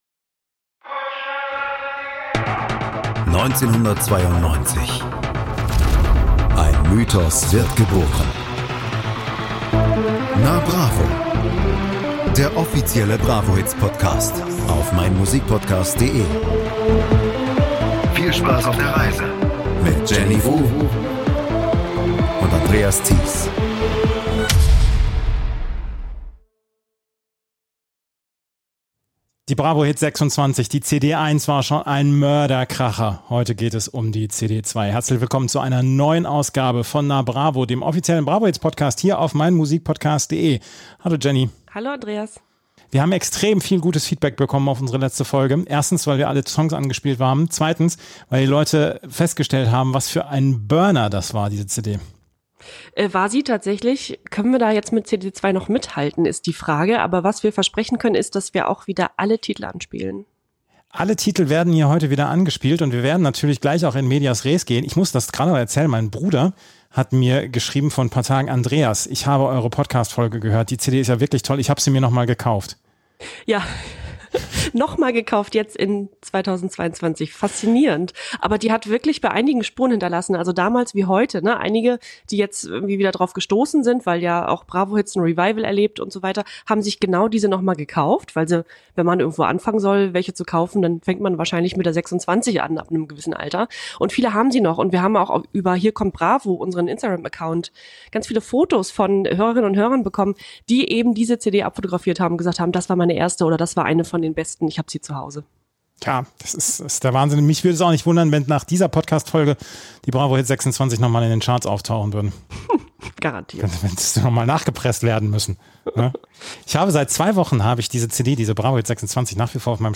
in dieser zweiten Folge besprechen sie die CD2 abwechselnd und spielen JEDEN Titel an.